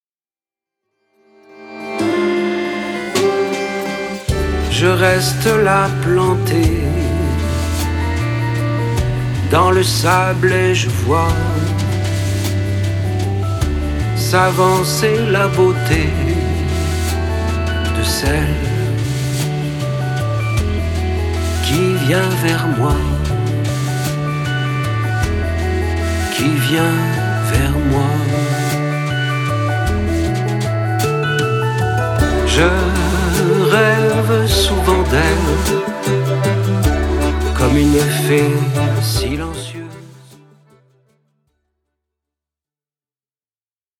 Sa voix qui chante et déclame nous interpelle.